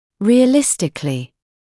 [ˌrɪə’lɪstɪklɪ][ˌриэ’листикли]реалистично